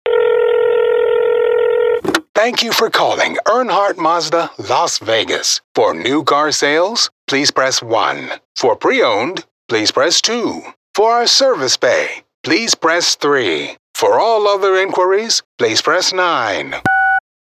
Male
American English
Natural, Smooth, Warm, Assured, Authoritative, Bright, Confident, Cool, Corporate, Deep, Engaging, Friendly, Gravitas, Versatile
Microphone: Neumann TLM103, Sennheiser MKH416